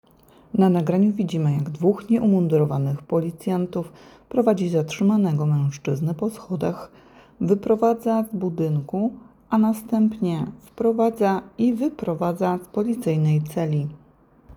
Nagranie audio Audiodeskrypcja_filmu.m4a